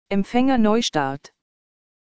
Anbei fehlende Systemsounds.